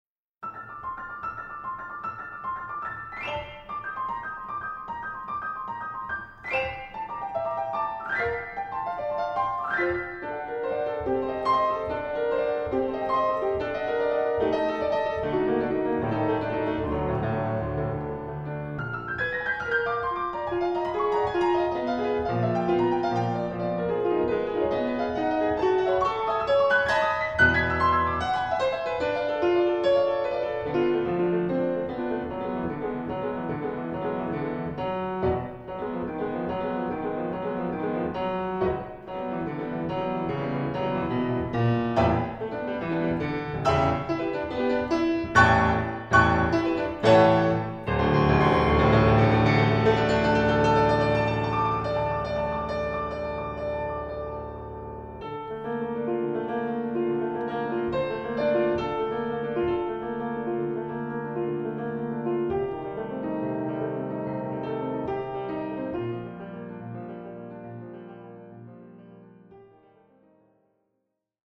Klavier
11 - Toccata for Piano